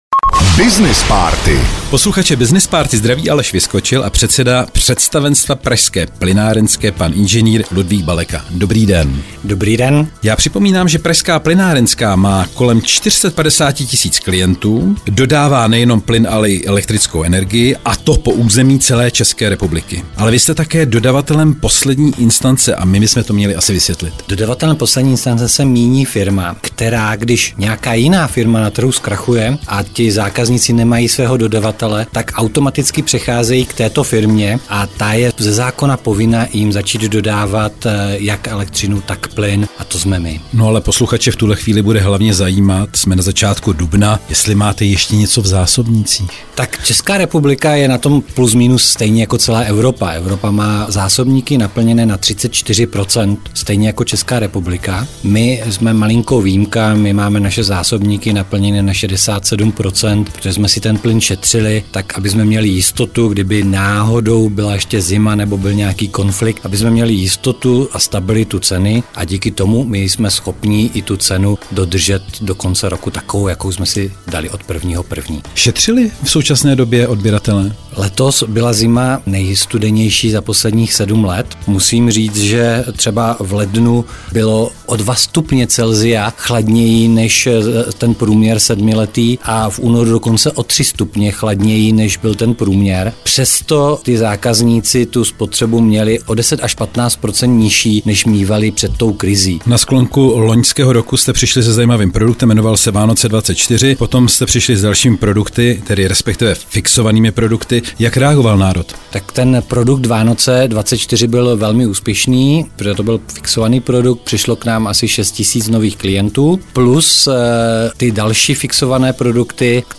Veškerá témata rozhovorů na Frekvenci 1 jsou dostupná na uvedených odkazech:
Rozhovor 1
rozhovor_01.mp3